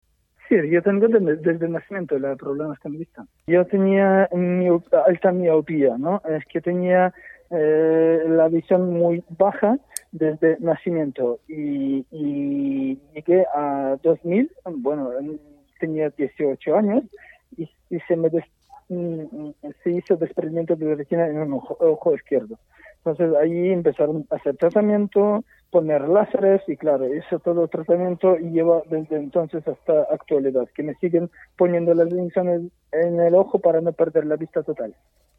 La escalada de violencia no había hecho más que empezar y multiplicarse desde que se realizara esta entrevista, vía teléfono móvil, en la tarde del jueves 10 de marzo.